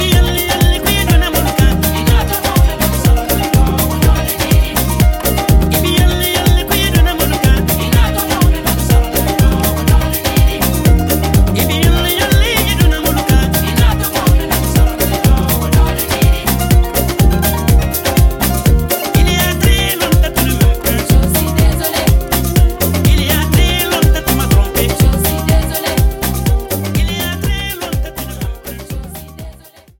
VOC